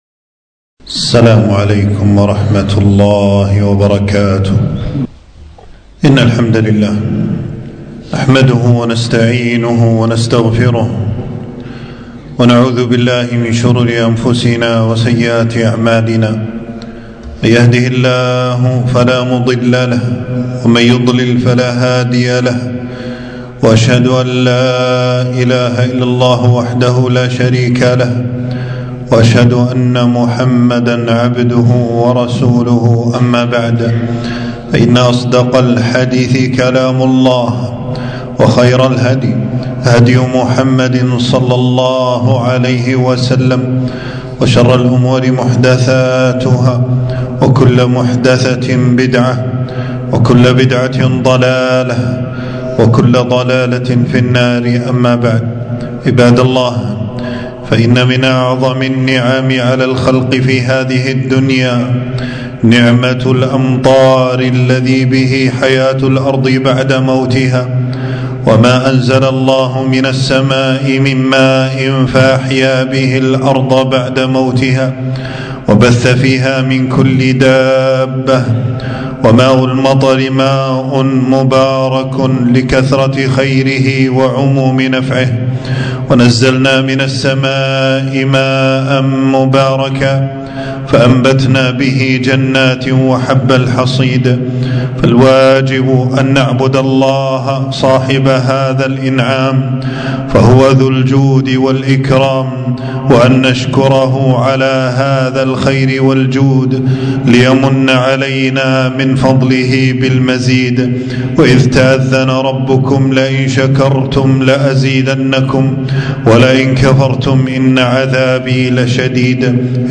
تنزيل تنزيل التفريغ خطبة بعنوان: مطرنا بفضل الله ورحمته .
حفظه الله تعالى المكان: في مسجد السعيدي بالجهراء